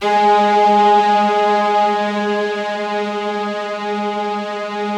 BIGORK.G#2-L.wav